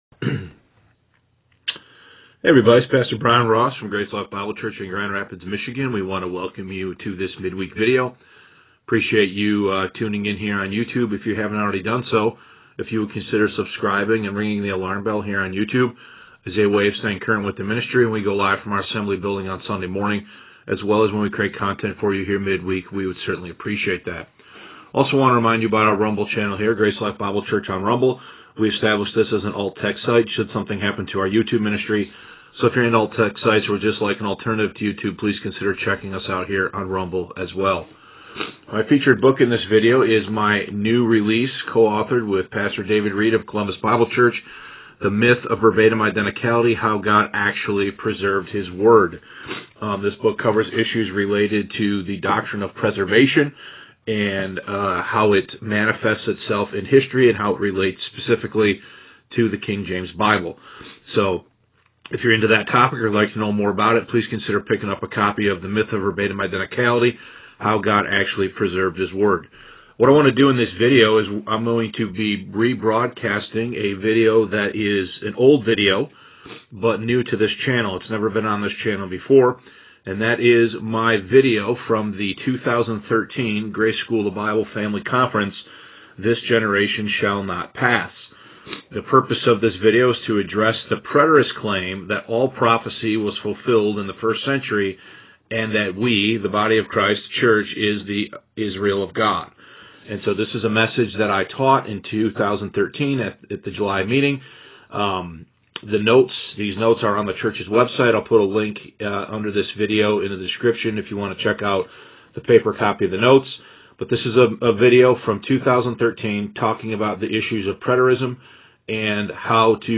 This Generation Shall Not Pass (2013 GSB Conference Message)